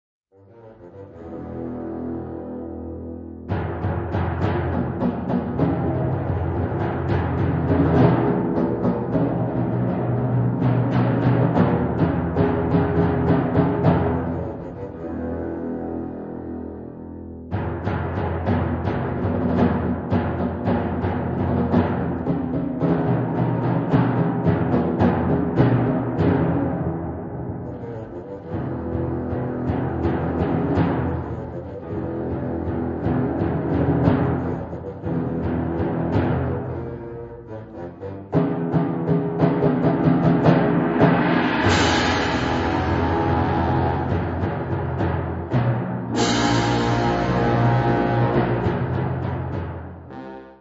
Catégorie Harmonie/Fanfare/Brass-band
Sous-catégorie Suite
Instrumentation Ha (orchestre d'harmonie); CB (Concertband)